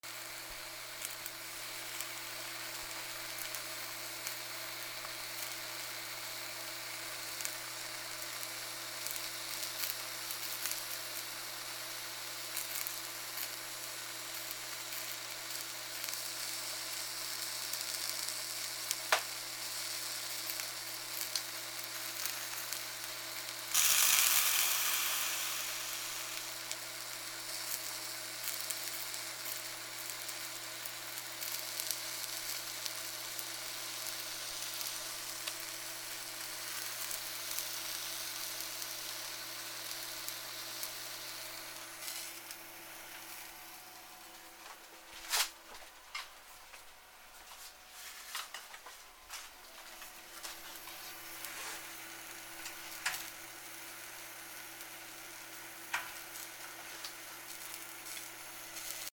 なすびを焼く 換気扇の雑音あり